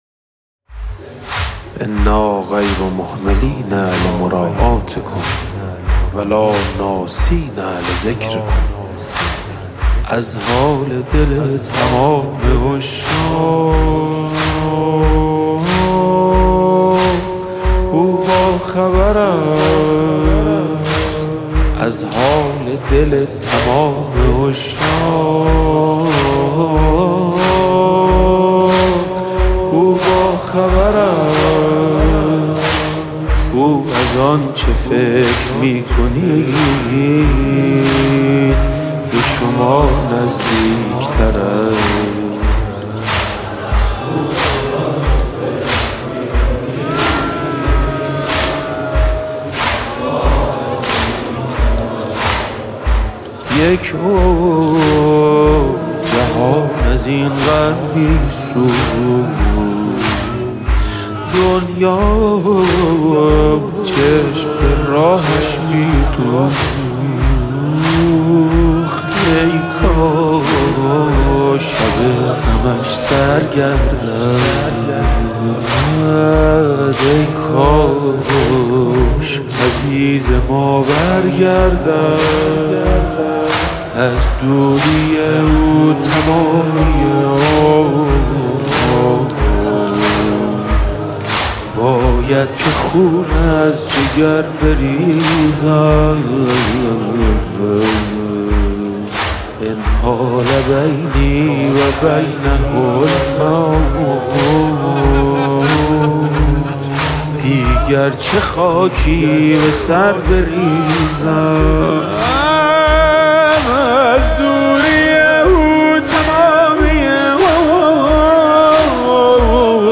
مذهبی
مناجات با امام زمان (عج)